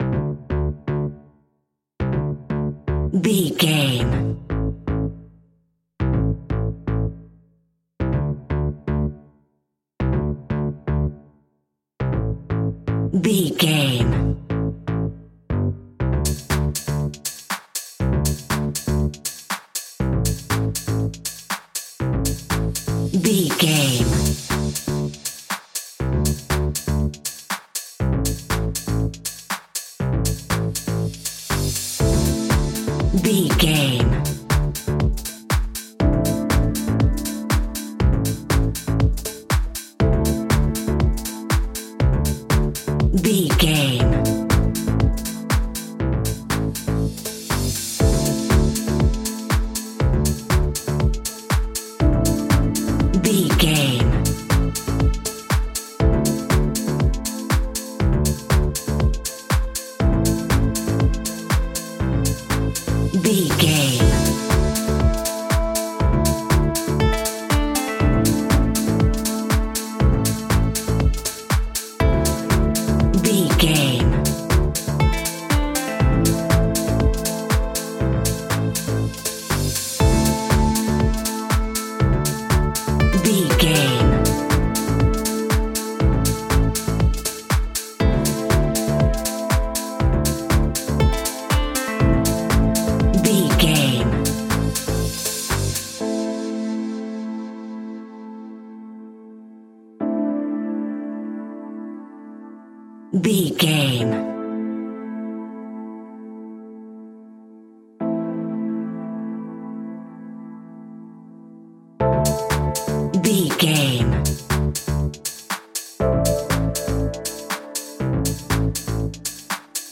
Aeolian/Minor
groovy
uplifting
energetic
repetitive
bass guitar
strings
electric piano
synthesiser
drum machine
funky house
deep house
nu disco
upbeat
funky guitar
wah clavinet
horns